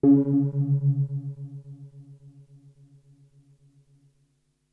描述：通过Modular Sample从模拟合成器采样的单音。
Tag: CSharp5 MIDI音符-73 DSI-利 合成器 单票据 多重采样